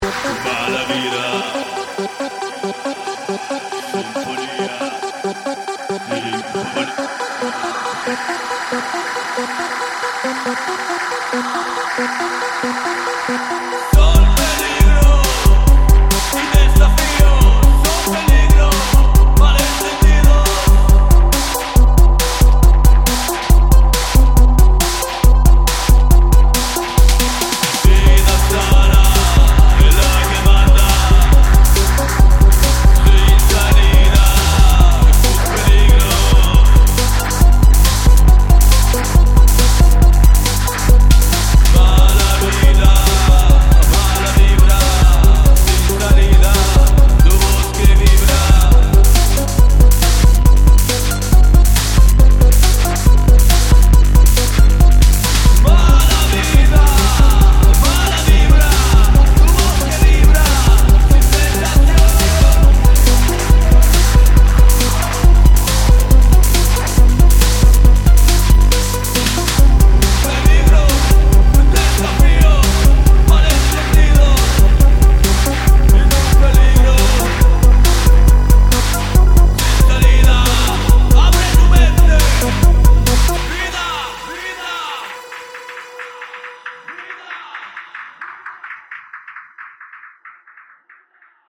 queer death techno
EBM , Techno